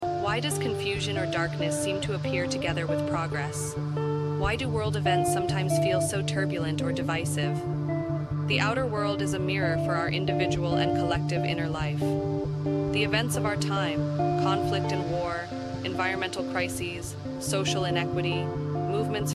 Guitar plus Ableton Live sampling, sound effects free download
Guitar plus Ableton Live sampling, guided by positive spoken word.